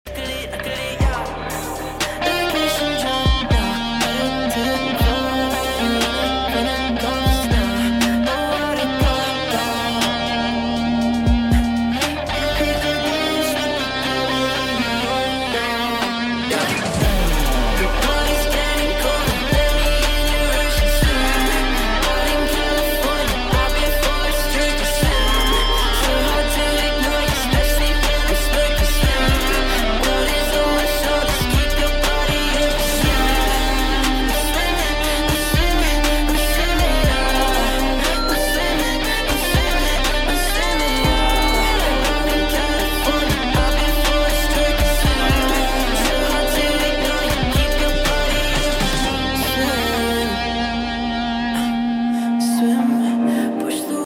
Guitar Cover